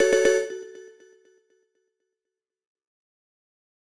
ゲーム・システム系効果音、第48弾！おしゃれな汎用っぽい効果音です！